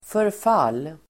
Uttal: [förf'al:]